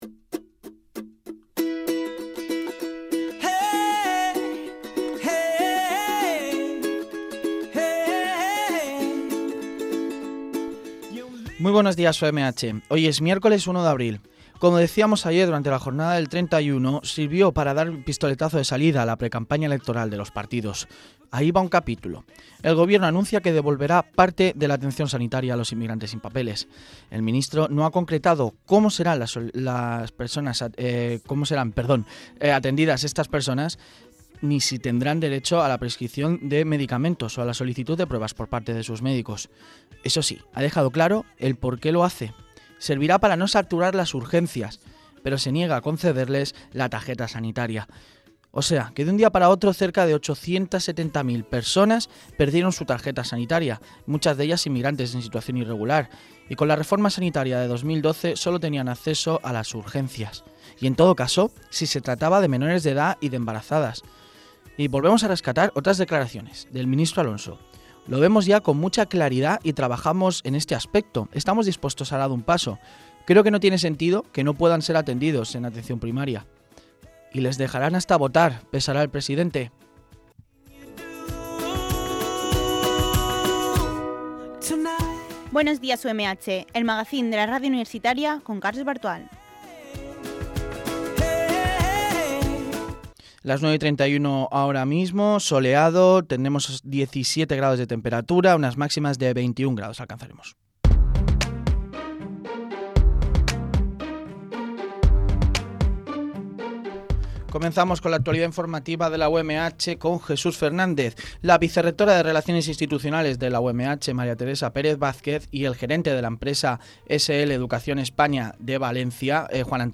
Magacín diario que incluye noticias de la actualidad informativa y secciones elaboradas por estudiantes de la titulación de Periodismo de la UMH.